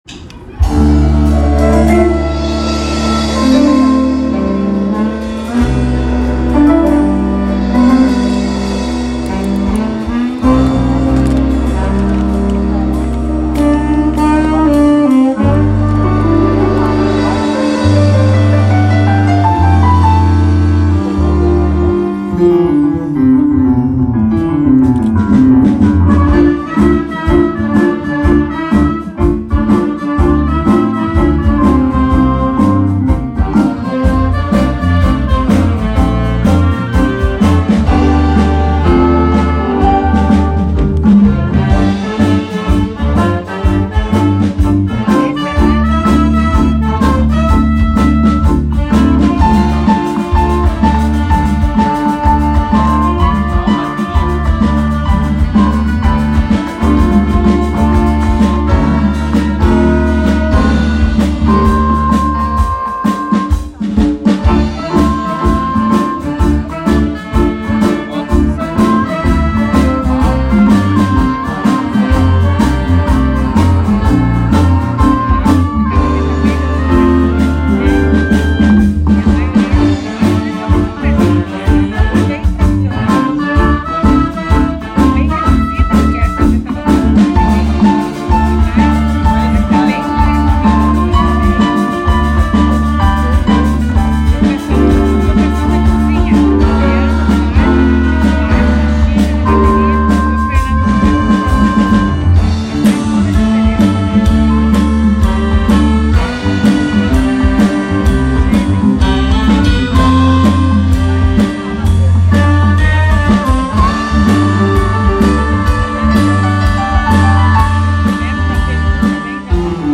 Versão de “Nada será como antes” de Milton Nascimento por orquestra Ensax